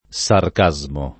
sarcasmo [ S ark #@ mo ] s. m.